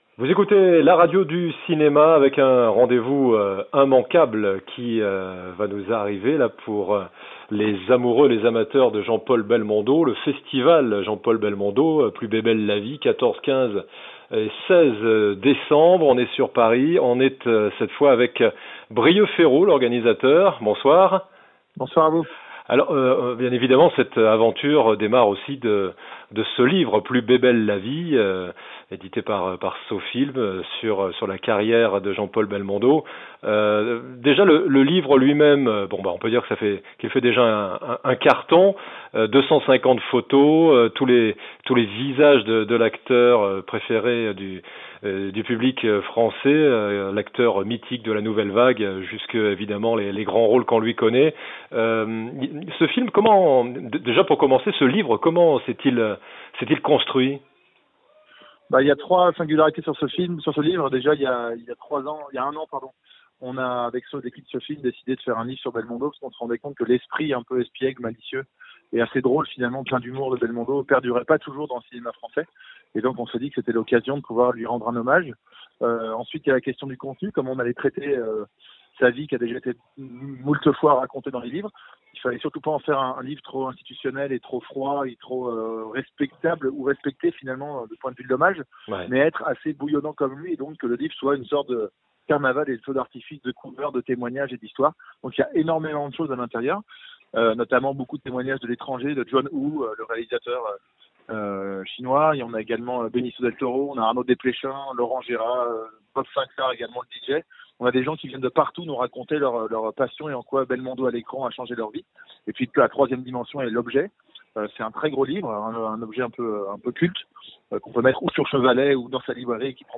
2. Podcasts cinéma : interviews | La Radio du Cinéma